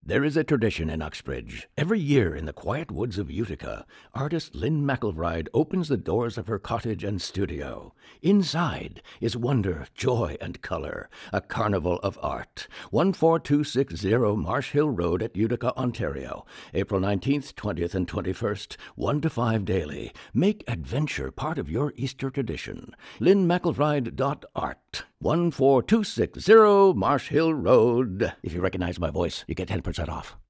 spoken-ad.wav